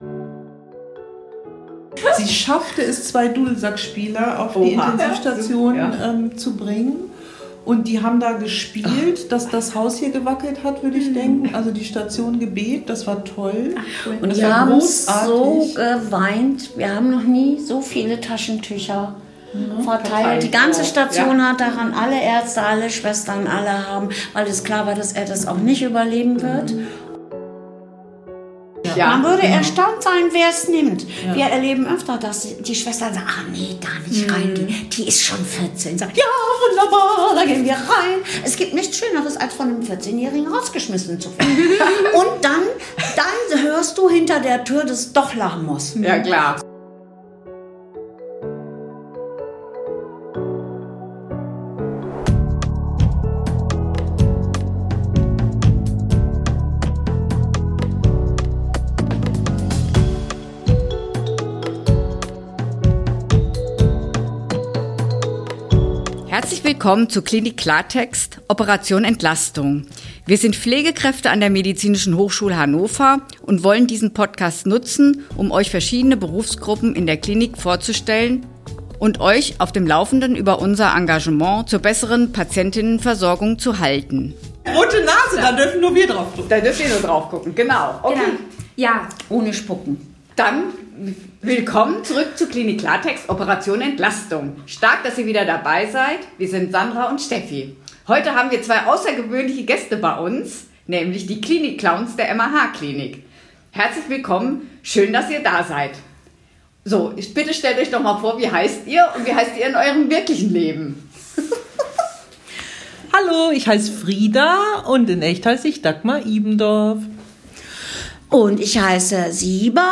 In unserer 30. (!) Folge haben wir ein besonders wildes Interview für euch. Unsere beiden Clinic-Clowns sprechen mit uns über ihre Werdegänge und den vielseitigen Job der Klinikclowns.